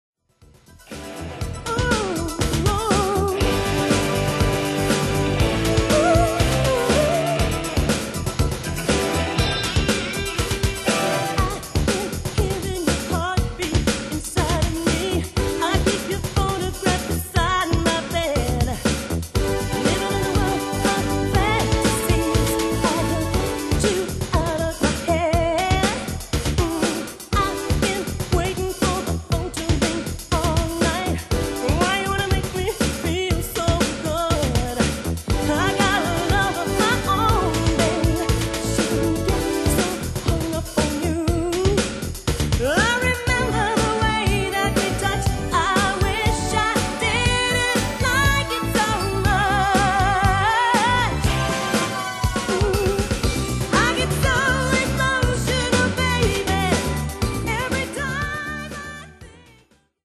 Multiplex (mit und ohne Gesang)